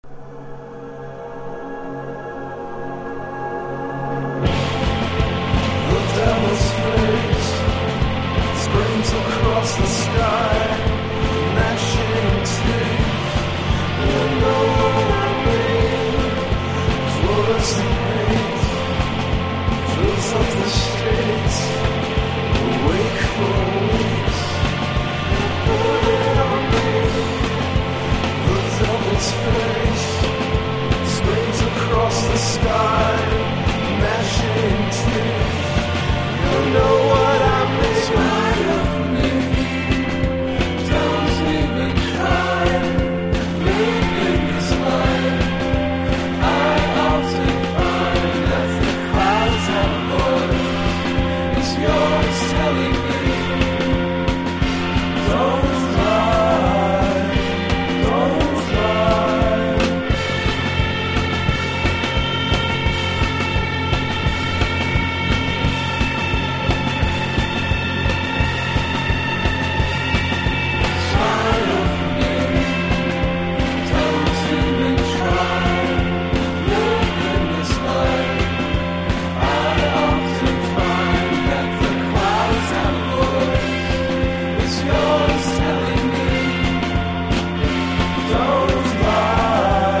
サイケなギター・サウンドが より前面に出たおすすめ盤！！